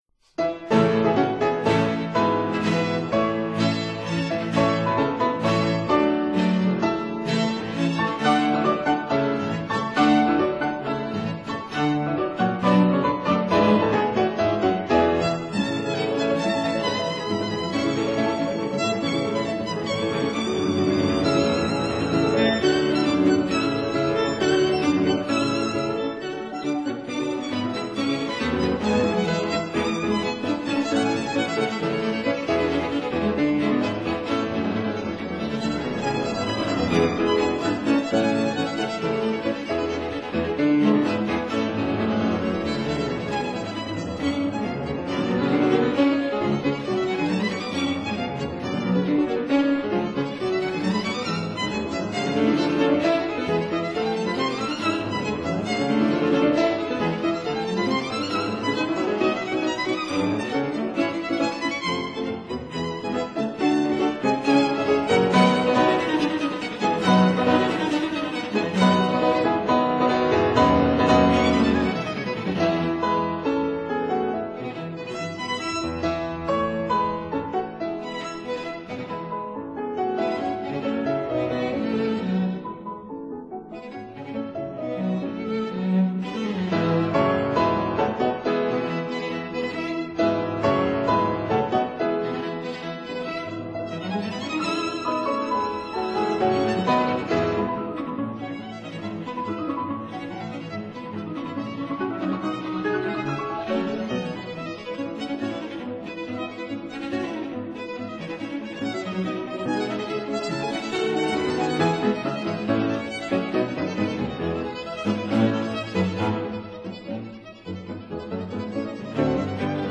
violin
cello
piano Date